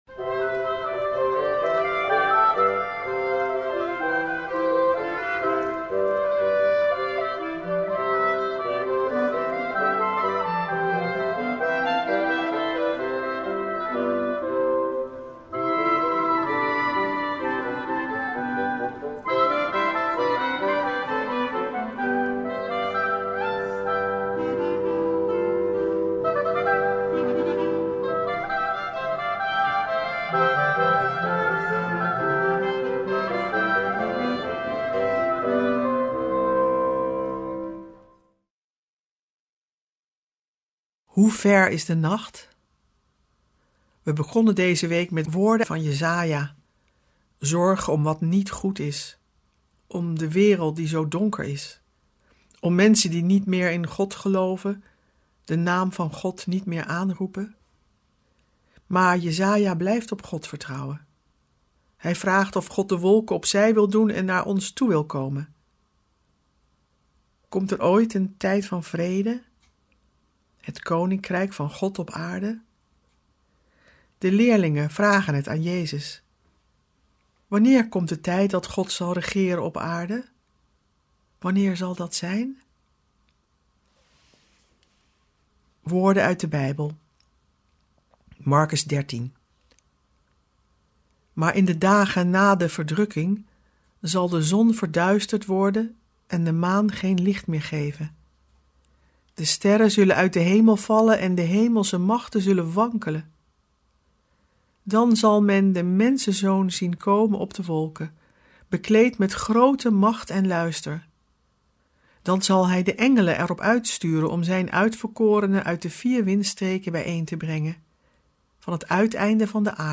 Ook dit jaar komen klassieke Bijbelteksten aan de orde, teksten die al eeuwen in de kerk juist in deze weken van Advent zijn gelezen. We verbinden ze met onze tijd en we luisteren naar prachtige muziek en poëzie.